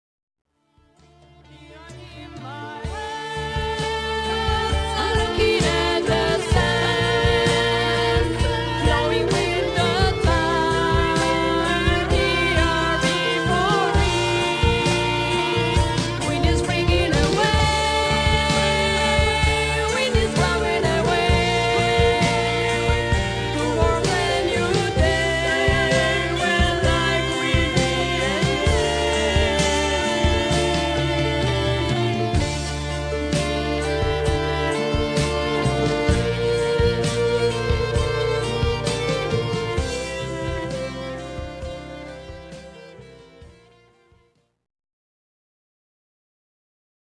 vocals, all guitars, bass guitar, 22 strings harp.
piano, keyboards, bass guitar, flute and all recorders.
drums